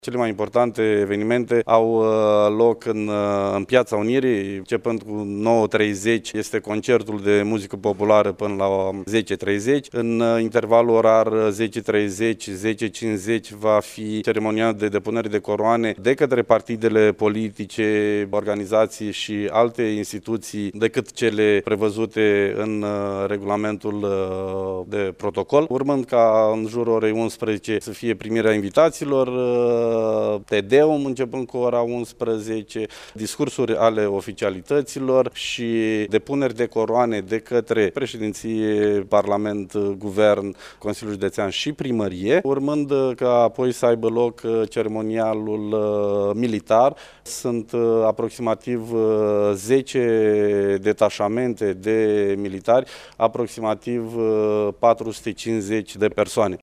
Mâine, evenimentele organizate pentru a marca 160 de ani de la Unirea Principatelor încep la ora 9:30 în Piaţa Unirii, a declarat prefectul Marian Şerbescu: